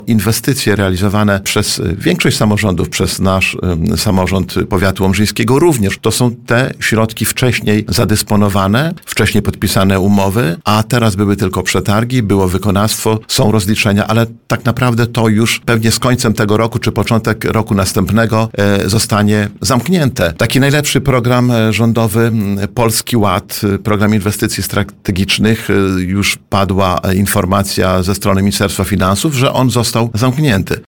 Starosta łomżyński Lech Szabłowski przyznał na antenie Radia Nadzieja, że samorządom z regionu coraz trudniej otrzymywać rządowe dofinansowanie.